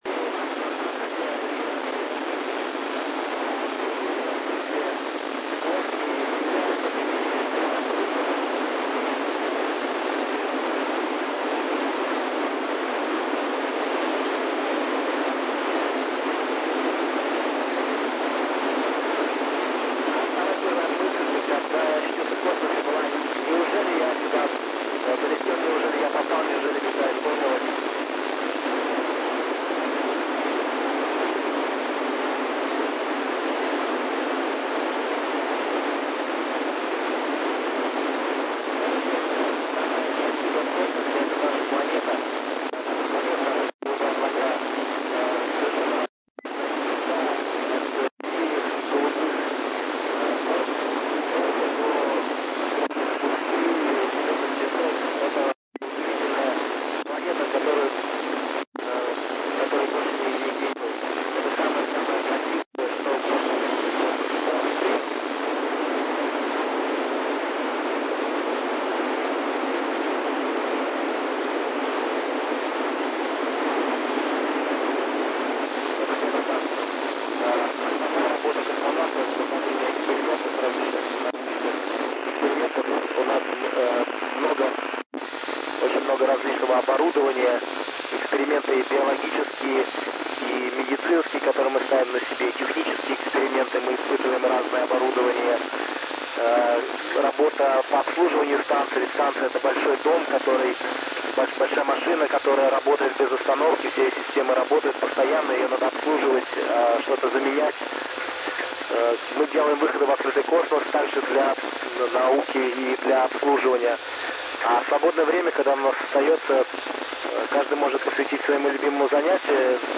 Сеанс радиосвязи МКС с г. Серпейск (Россия), частота 145,800 мГц.
05 апреля 2021 года российские космонавты с борта МКС провели сеанс радиосвязи с г. Серпейск (Россия). Частота приёма 145.800 MHz.